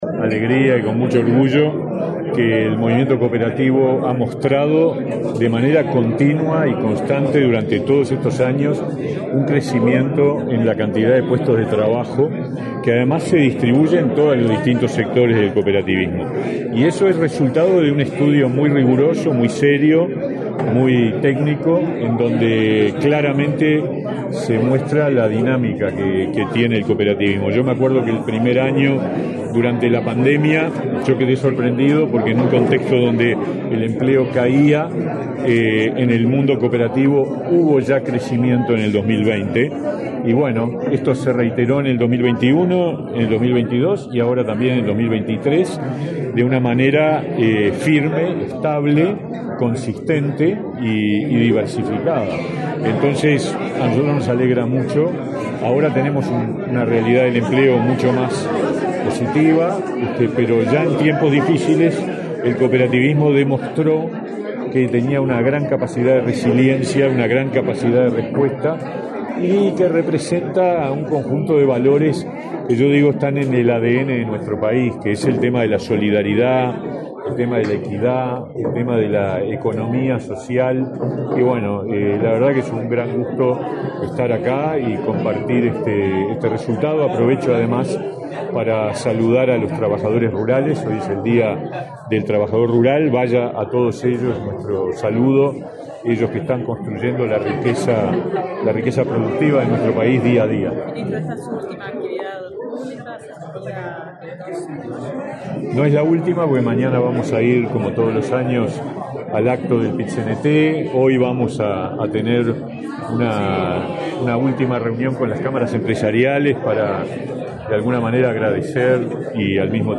Declaraciones del ministro de Trabajo, Pablo Mieres
Declaraciones del ministro de Trabajo, Pablo Mieres 30/04/2024 Compartir Facebook X Copiar enlace WhatsApp LinkedIn Este martes 30 en la sede del Instituto Nacional del Cooperativismo, el ministro de Trabajo, Pablo Mieres, participó en la presentación del informe de empleo en cooperativas y sociedades de fomento rural. En la oportunidad, dialogó con la prensa.